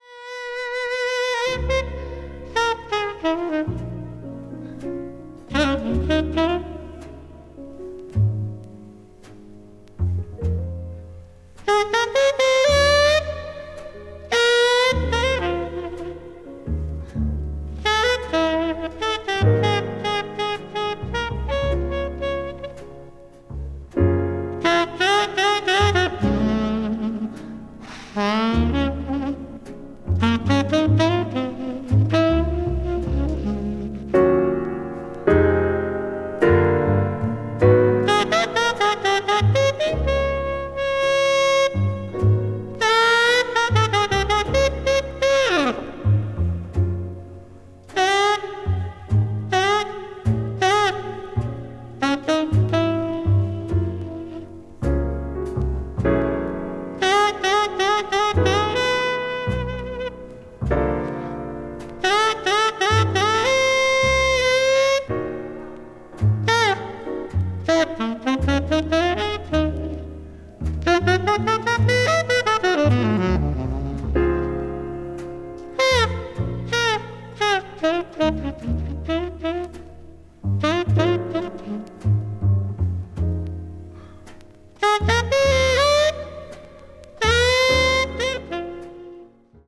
そのタイトルのとおり、全編非常にしっとりとしたムードでゆっくりと展開するジャズ・バラードを全編で演奏した作品。
bop   jazz ballad   modern jazz